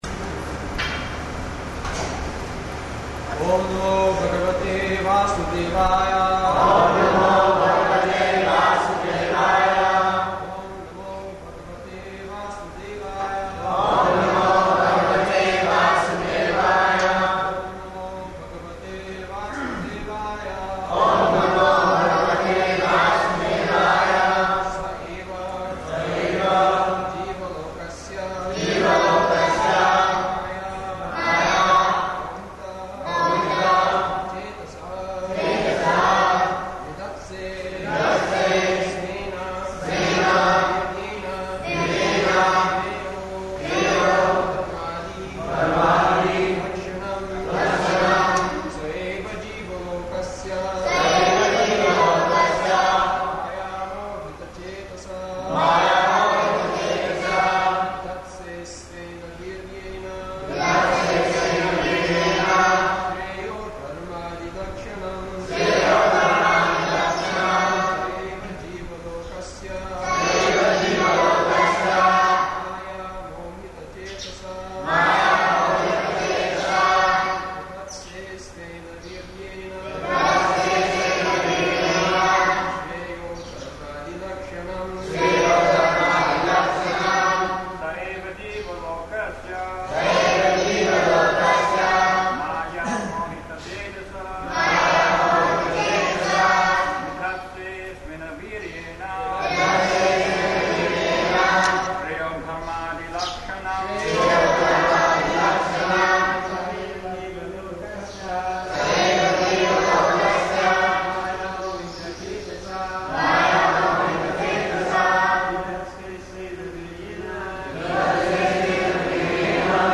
September 21st 1976 Location: Vṛndāvana Audio file
[devotees repeat] [chants verse]